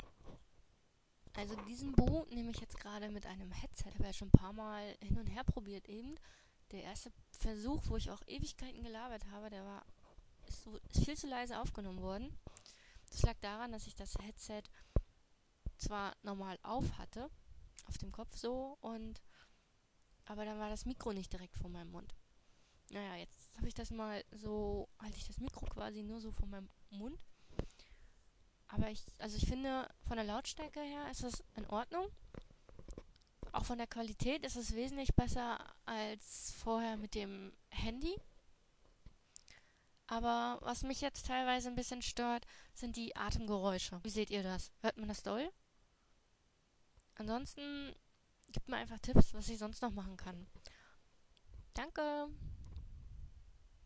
Test-Boo mit Headset